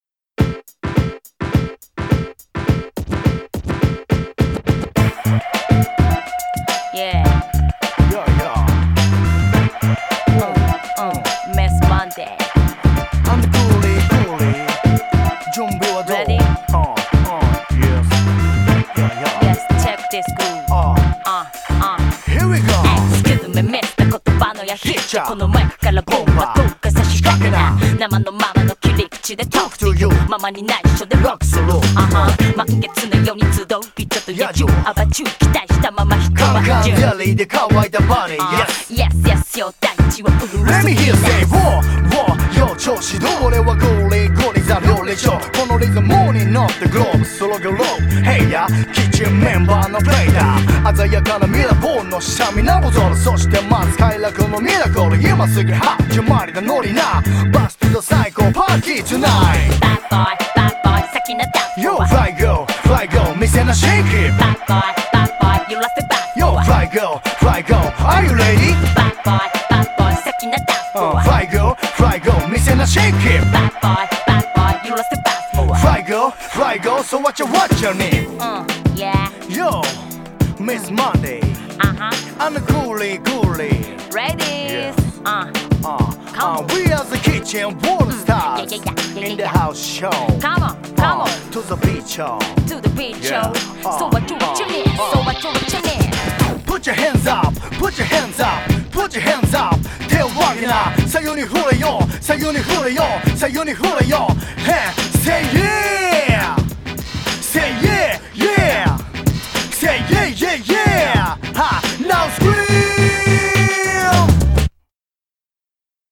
BPM105
Audio QualityPerfect (High Quality)
Genre: HIP HOP.